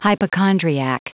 Prononciation du mot hypochondriac en anglais (fichier audio)
hypochondriac.wav